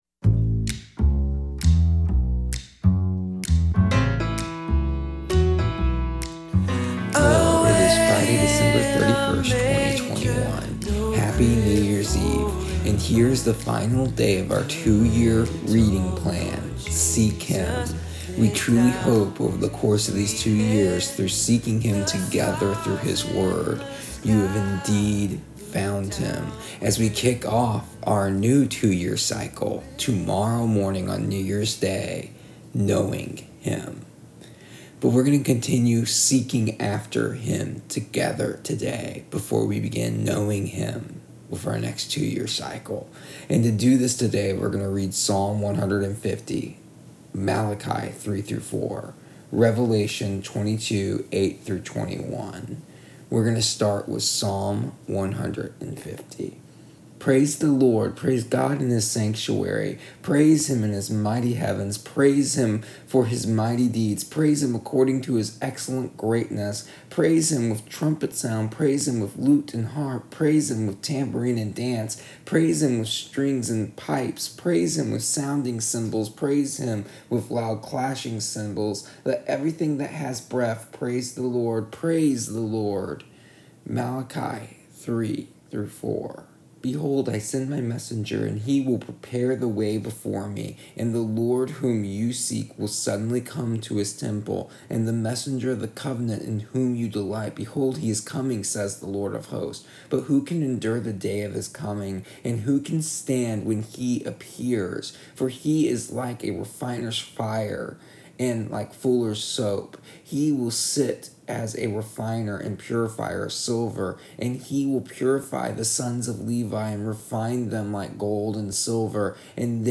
Here is the audio version of our daily readings from our daily reading plan Seek Him for December 31st, 2021.